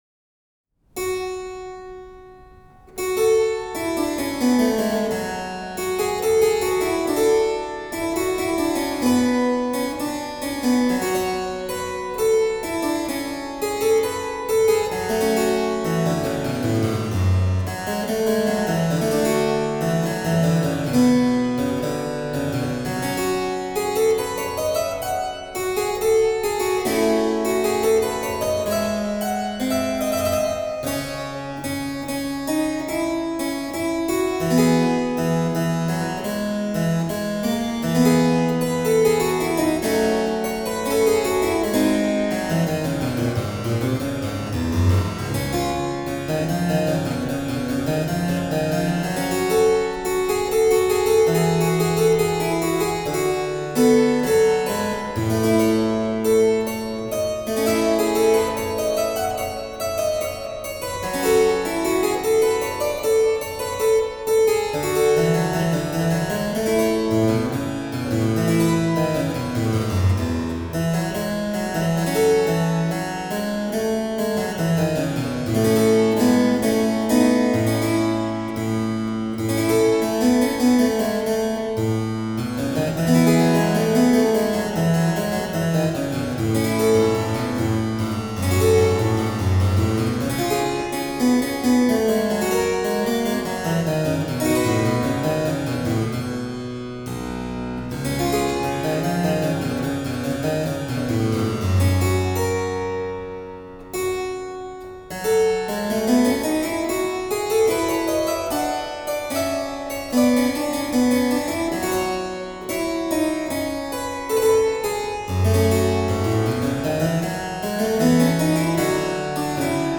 Volume 3 consists of harpsichord music